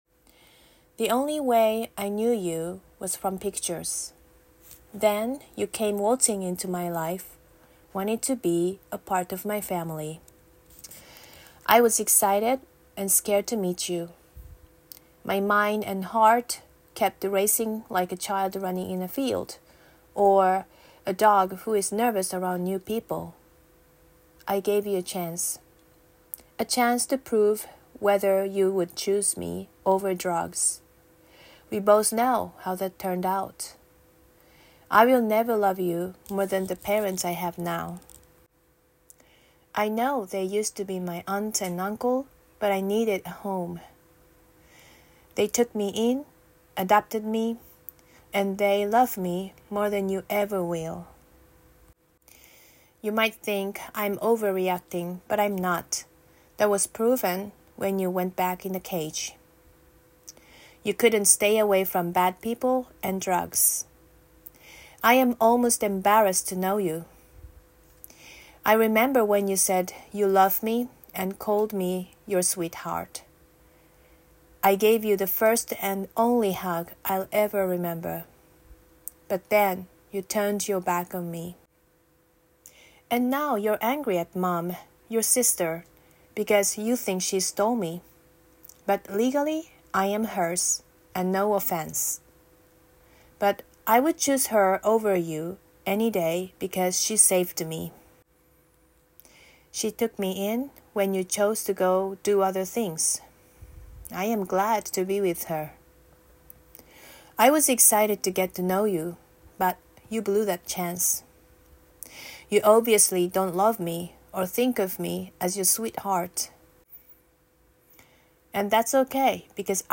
“Racing Thoughts”(EED Monologues for Teens)
Genre: Dramatic